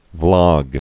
(vlŏg)